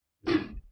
描述：在飞行途中飞行员开枪射击。
Tag: 弱爆破